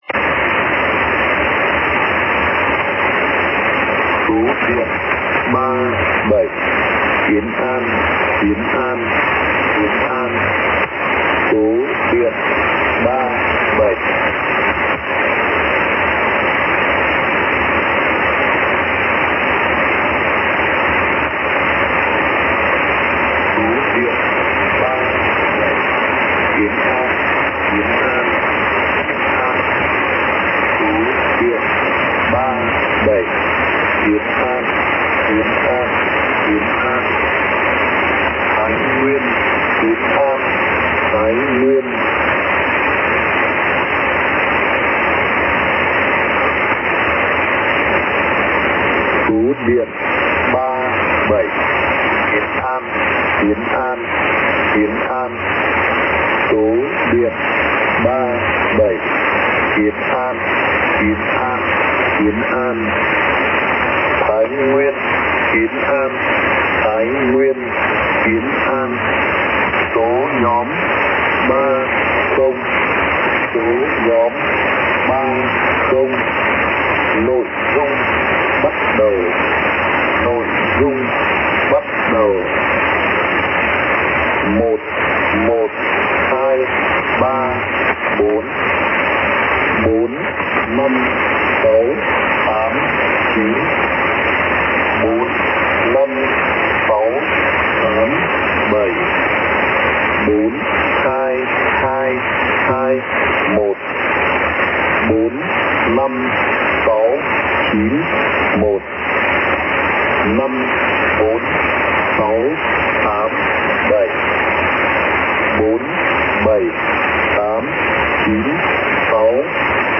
The station was present, but this time a male (OM) voice.
The recording I made again did not include the entire transmission as I tuned to it right at the end.